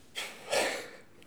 soulagement_01.wav